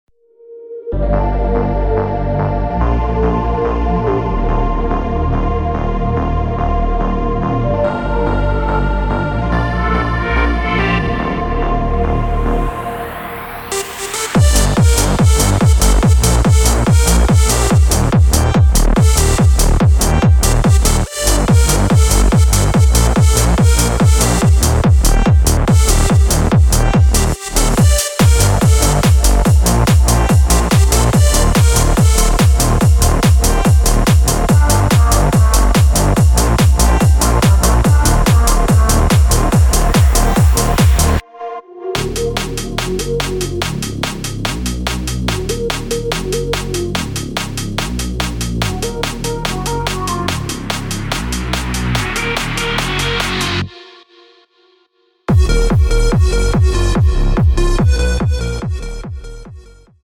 Melodic Techno